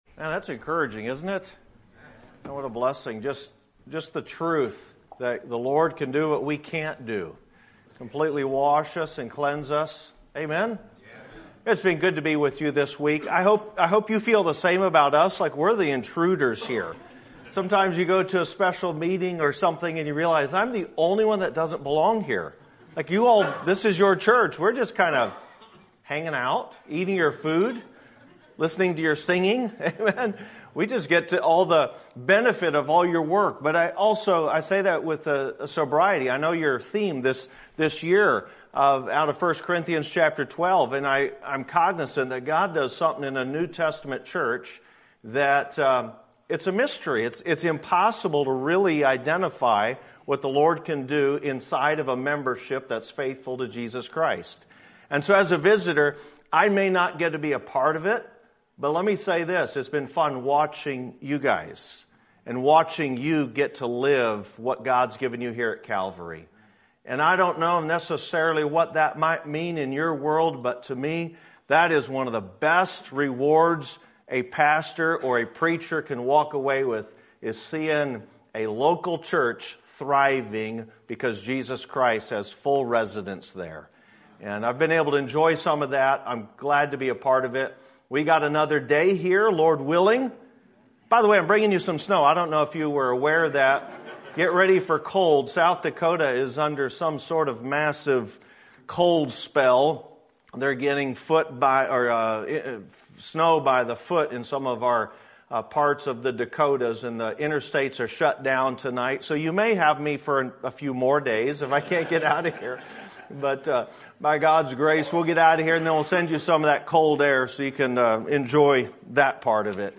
Sermon Topic: Winter Revival Sermon Type: Special Sermon Audio: Sermon download: Download (24.39 MB) Sermon Tags: 2 Peter Faith Peter Godliness